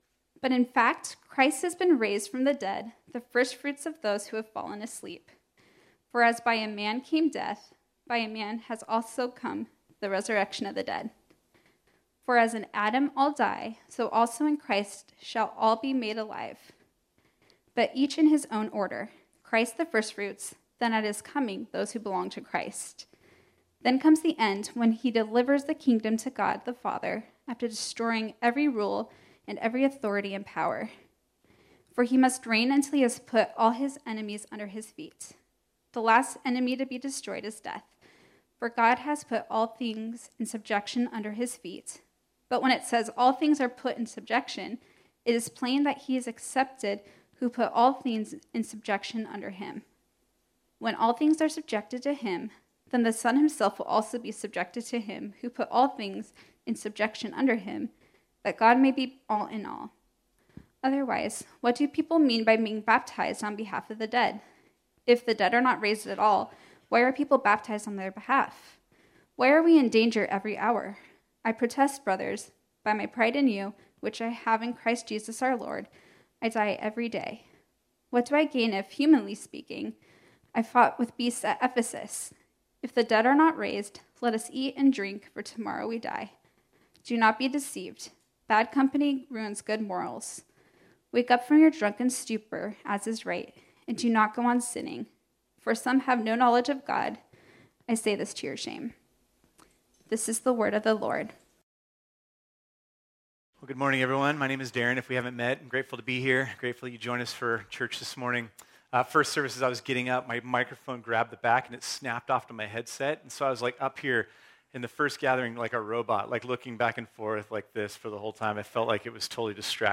This sermon was originally preached on Sunday, April 3, 2022.